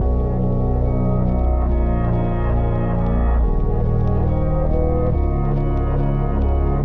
钢琴垫循环
描述：反转的环境钢琴与里斯的低音。
Tag: 140 bpm Hip Hop Loops Piano Loops 1.15 MB wav Key : D